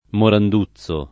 [ morand 2ZZ o ]